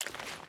Footsteps / Water / Water Walk 1.wav
Water Walk 1.wav